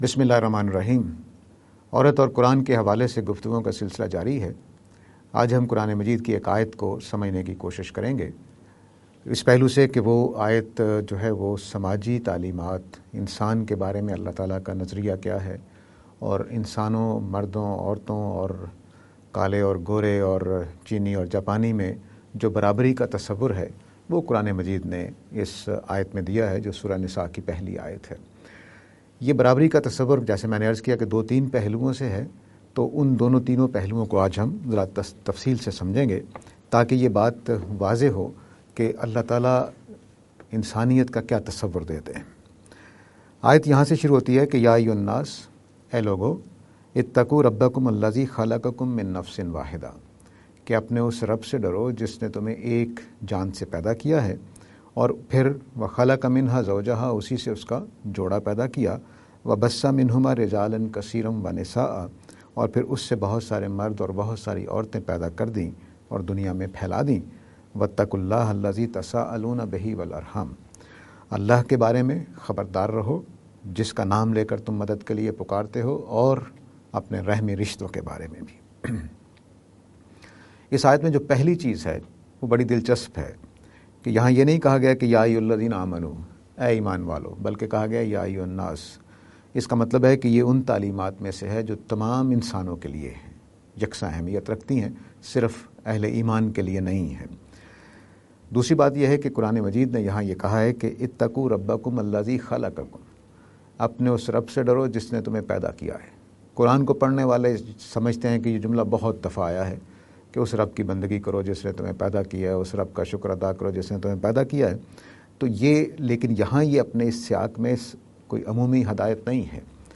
lecture series on "Women and Islam"